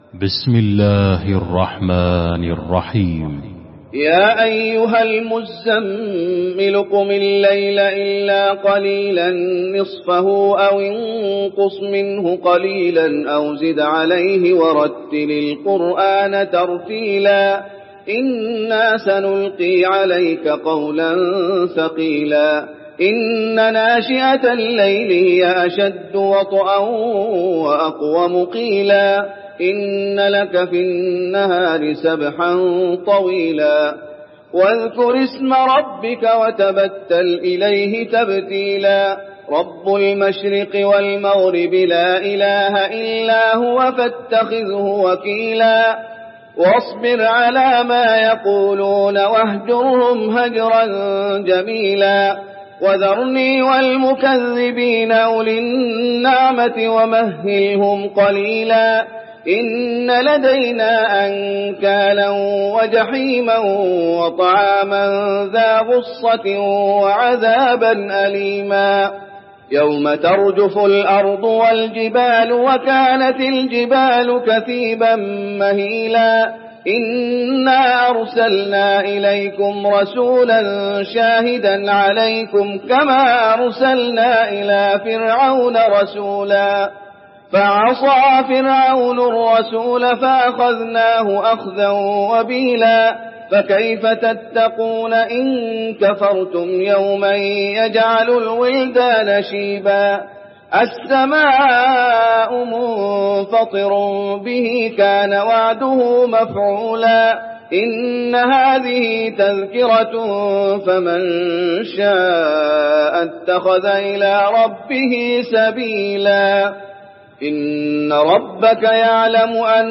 المكان: المسجد النبوي المزمل